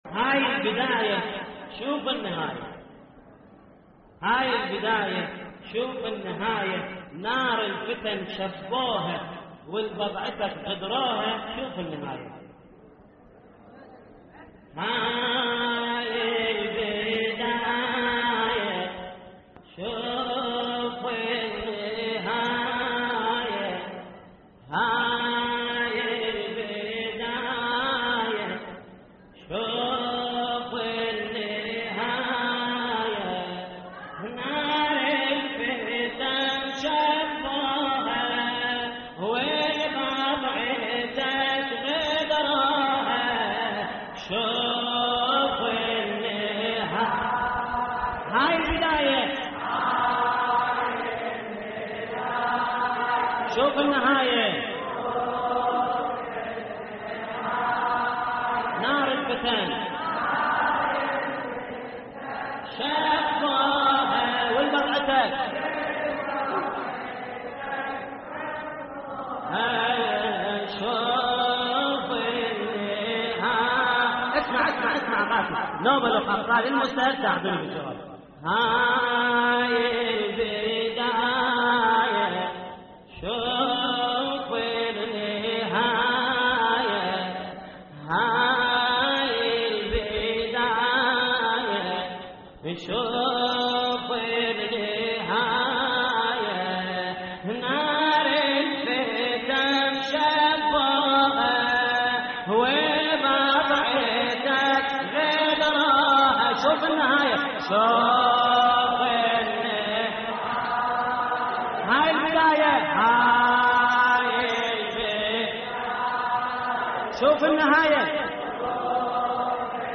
تحميل : هاي البداية شوف النهاية نار الفتن شبوها ولبضعتك غدروها / الرادود جليل الكربلائي / اللطميات الحسينية / موقع يا حسين